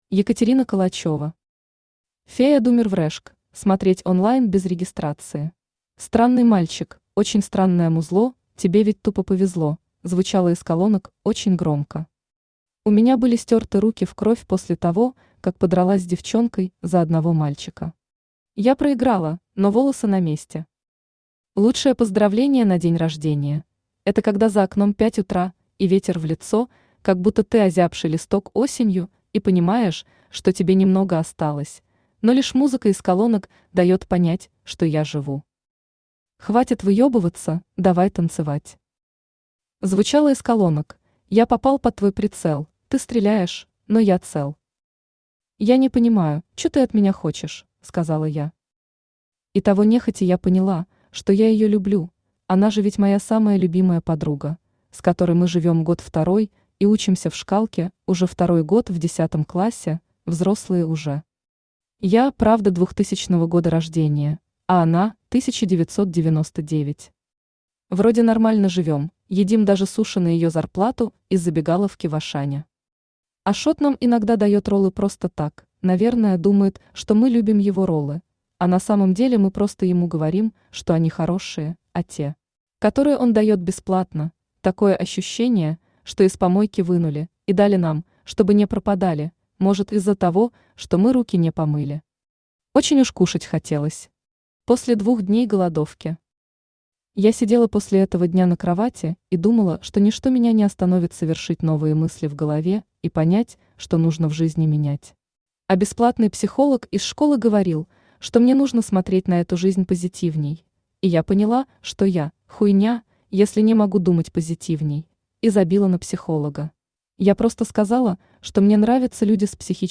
Аудиокнига Фея думер в rashke, смотреть онлайн без регистрации | Библиотека аудиокниг
Aудиокнига Фея думер в rashke, смотреть онлайн без регистрации Автор Екатерина Калачёва Читает аудиокнигу Авточтец ЛитРес.